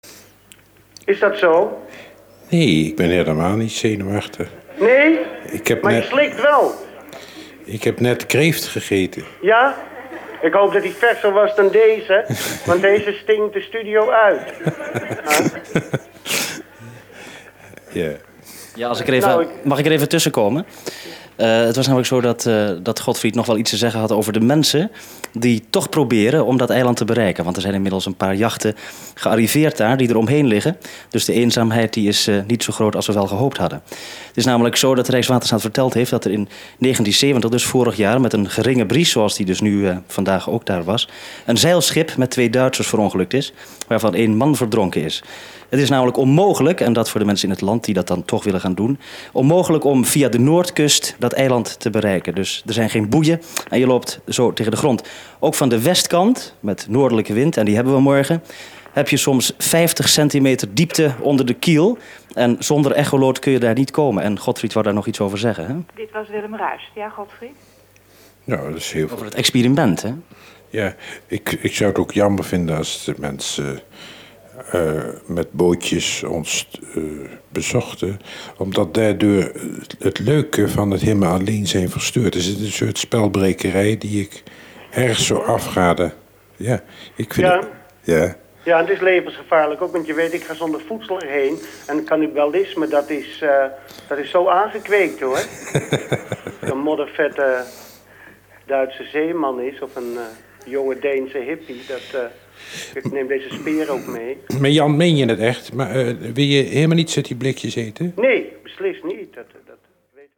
Het radioprogramma Alleen op een eiland: Dagboek van een eilandbewoner uit 1971 had een prachtig concept: schrijver woont in totale afzondering op onbewoond eiland. De enige verbinding met de bewoonde wereld is een radioverbinding. En zo kwam het dat het Waddeneiland Rottumerplaat in juli 1971 veertien dagen lang het toneel was van de schrijvers Godfried Bomans en Jan Wolkers. Radiopresentator Willem Ruis zocht vanuit het Groningse Warffum dagelijks contact met hen.